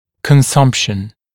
[kən’sʌmpʃn][кэн’сампшн]потребление